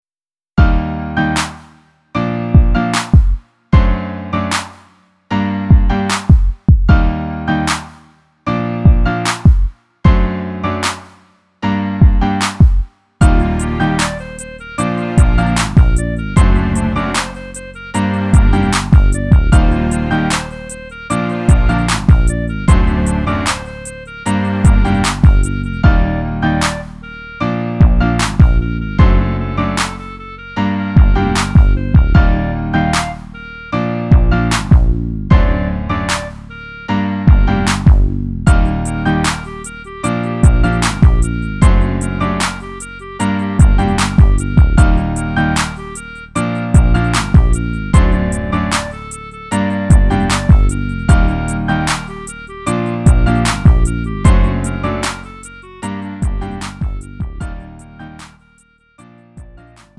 음정 -1키 4:02
장르 구분 Lite MR